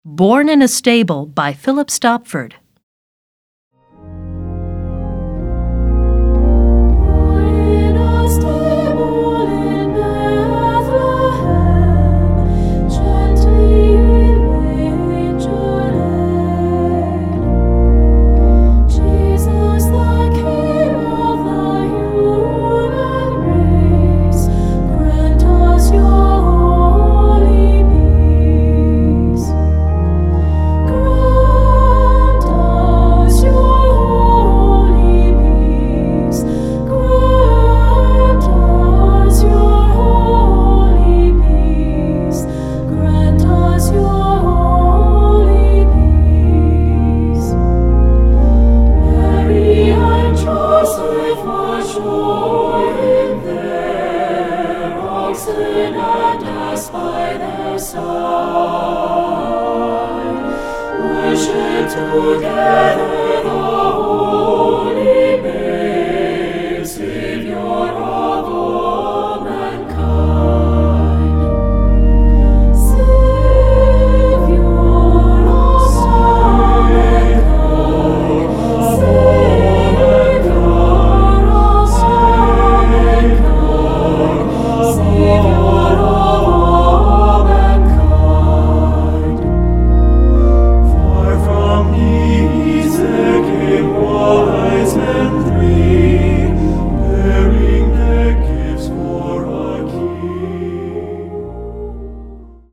Voicing: SATB and Organ